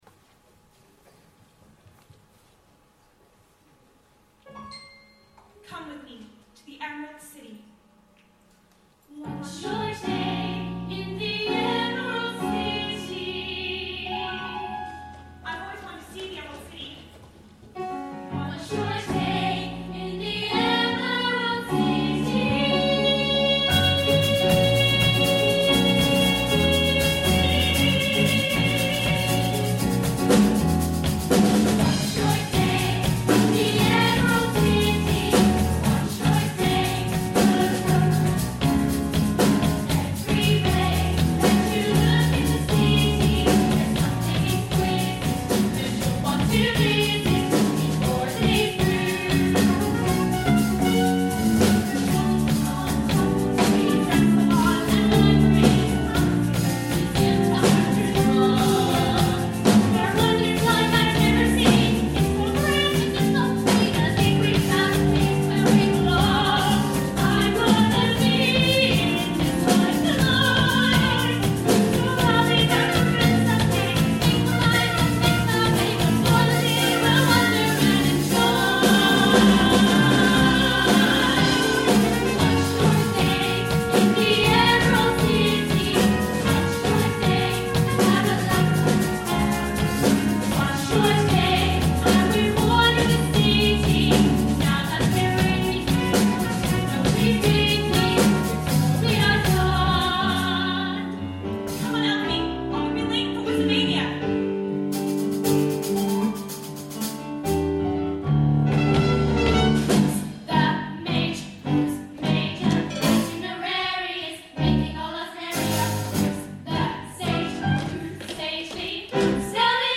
Musical Theatre
Summer Concert 2014